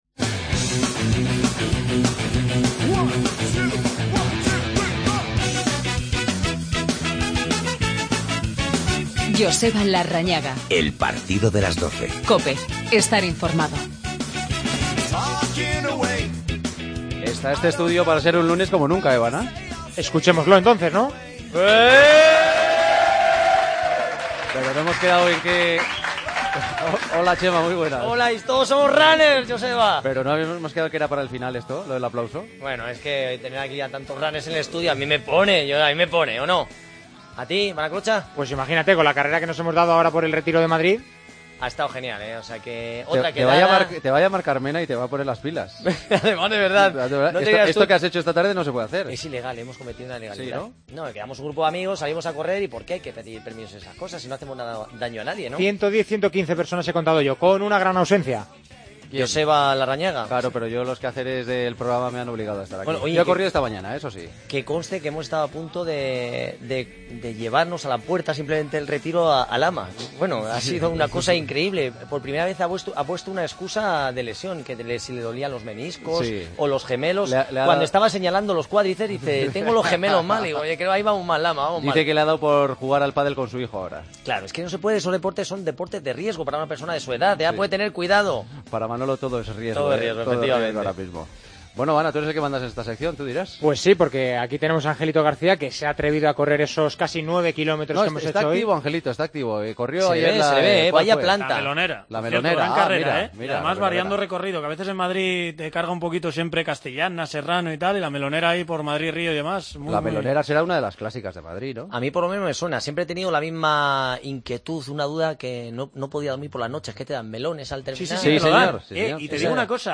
Redacción digital Madrid - Publicado el 15 sep 2015, 02:33 - Actualizado 13 mar 2023, 18:49 1 min lectura Descargar Facebook Twitter Whatsapp Telegram Enviar por email Copiar enlace Esta semana, consultorio con muchos invitados: todos los 'runners' de El Partido de las 12 que este lunes participaron en un entrenamiento junto a Chema Martínez en el Parque del Retiro.